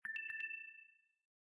LowBattery_InCall_EAR.ogg